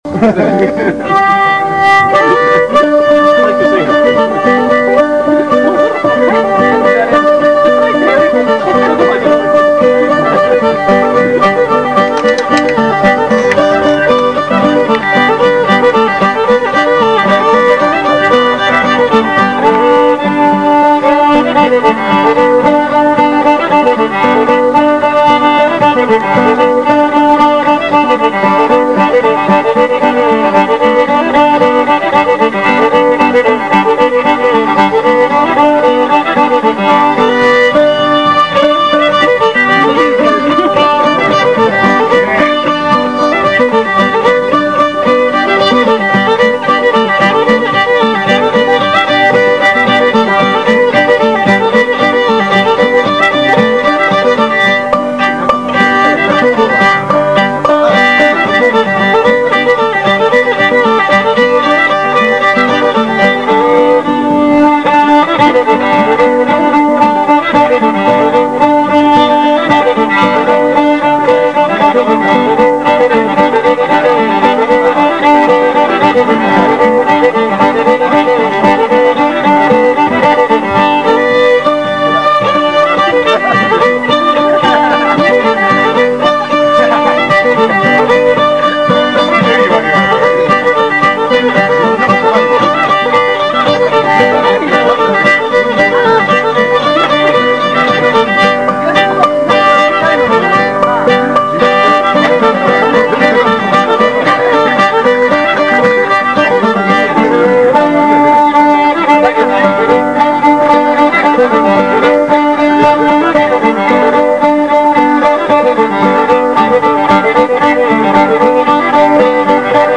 ** Old-Time JAM **
* 2004 TAKARAZUKA BLUEGRASS FEST.